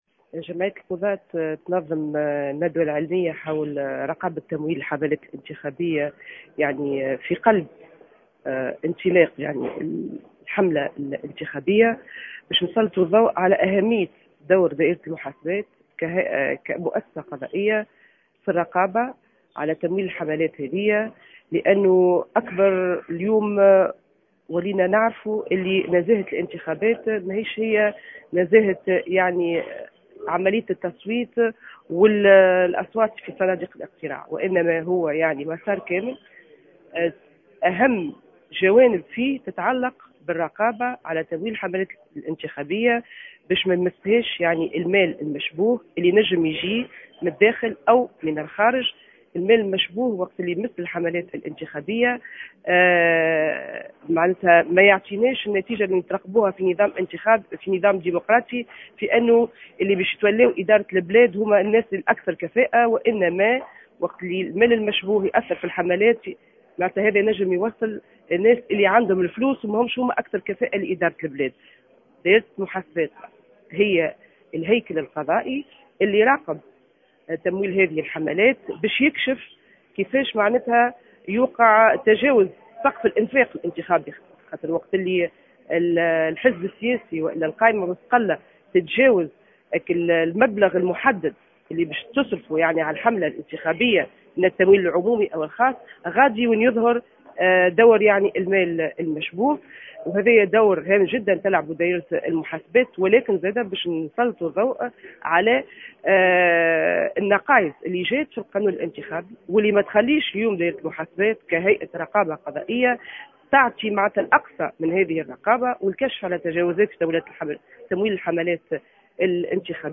a indiqué ce samedi 11 octobre 2014 dans une intervention sur les ondes de Jawhara FM